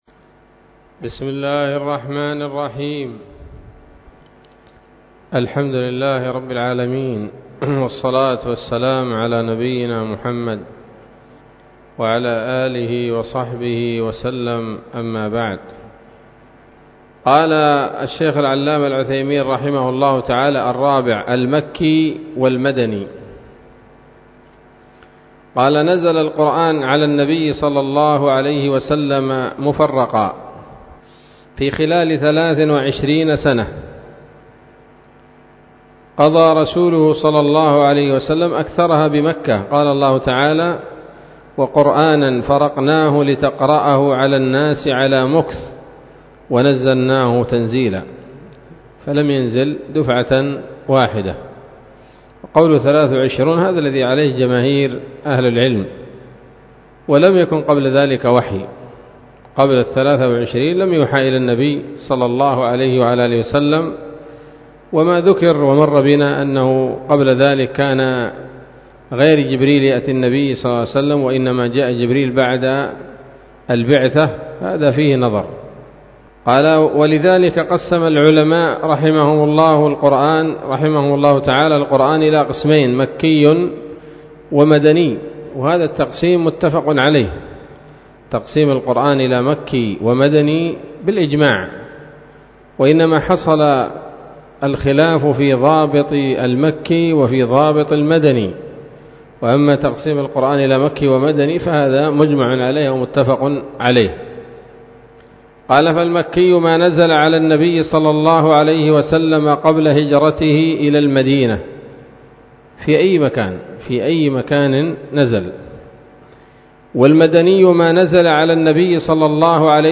الدرس الحادي عشر من أصول في التفسير للعلامة العثيمين رحمه الله تعالى 1446 هـ